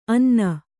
♪ anna